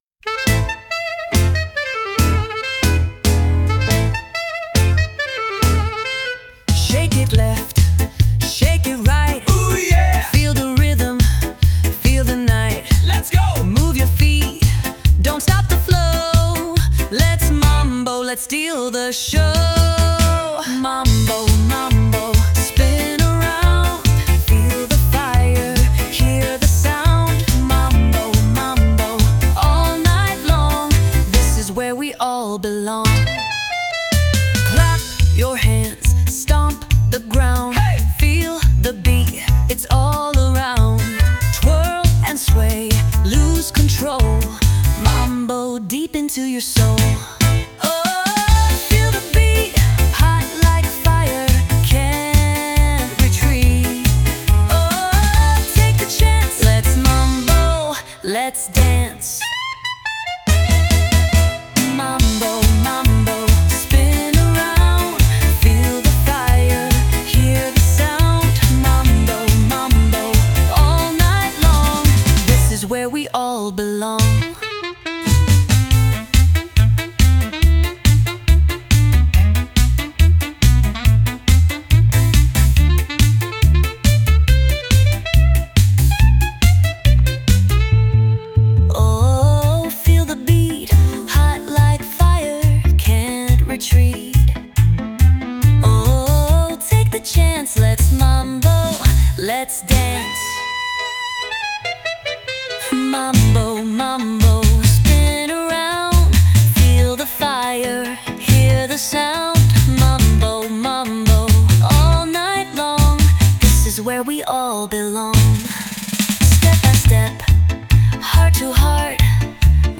🌍 Catchy, groovy, and made to move.
brings playful beats and an easy-to-dance tempo,